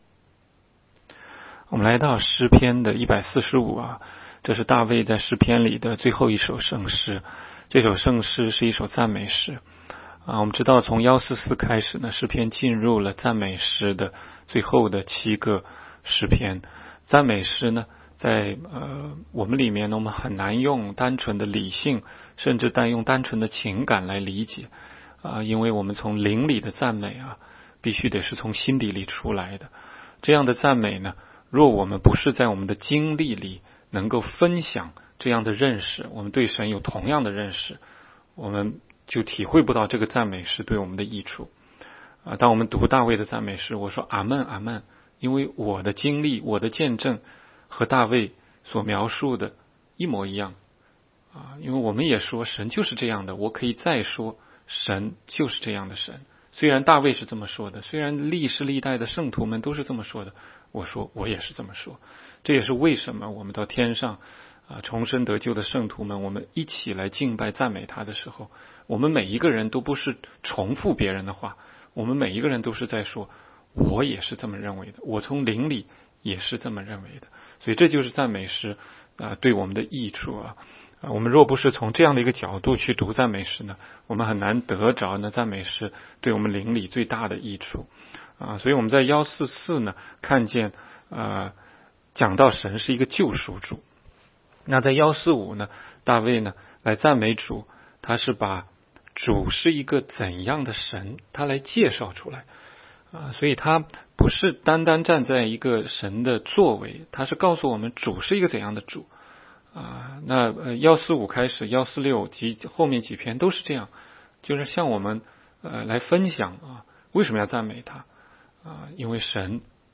16街讲道录音 - 每日读经 -《 诗篇》145章